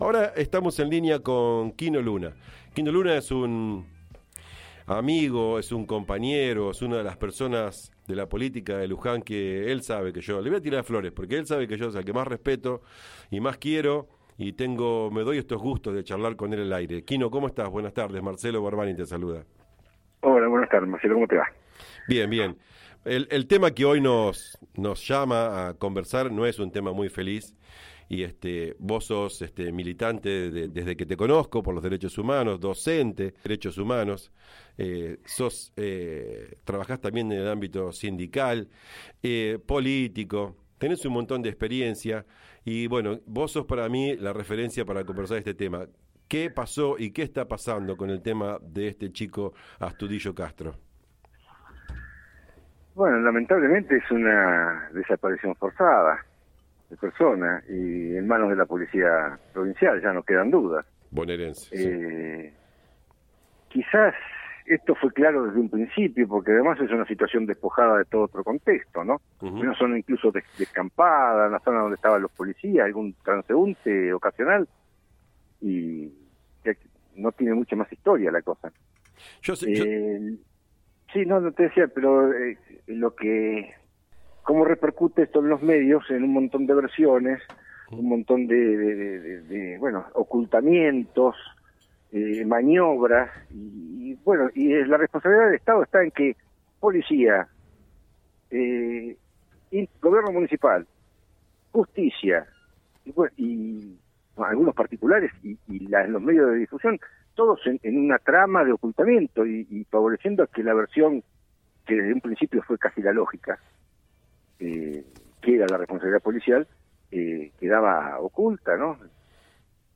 En conversación